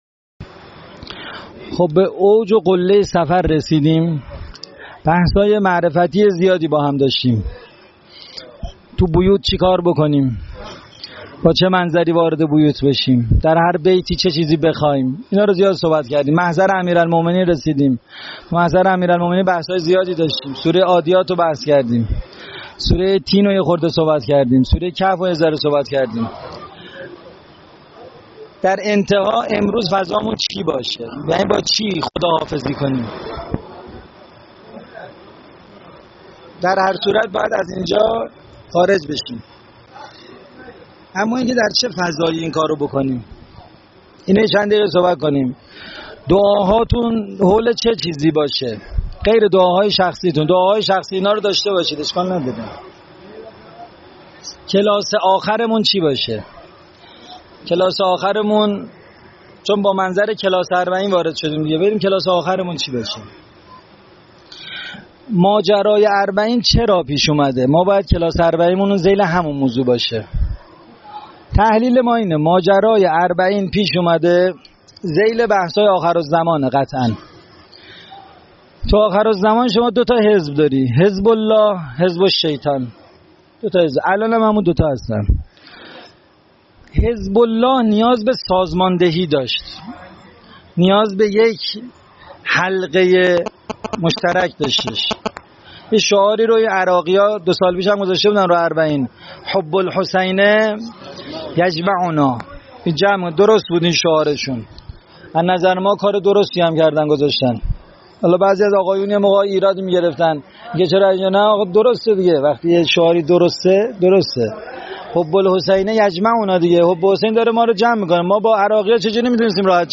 فایل صوتی سخنرانی